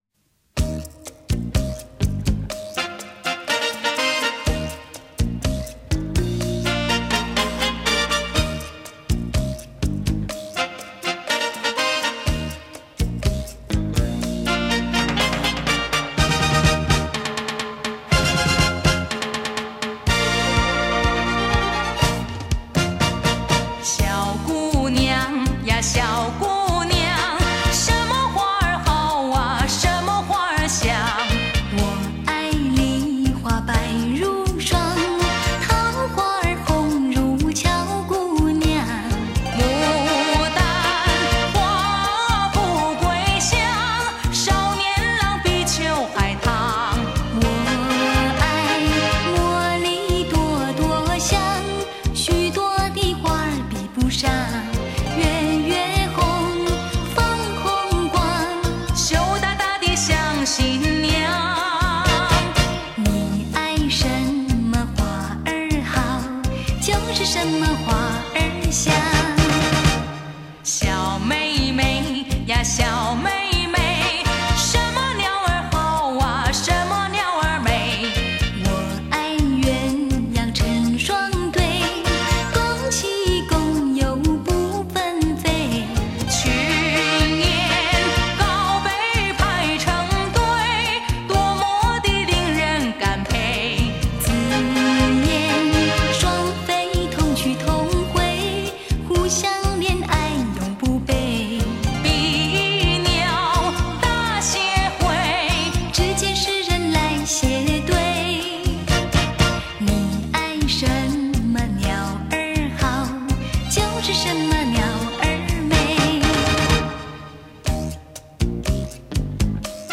经典怀旧老歌 群星汇集演绎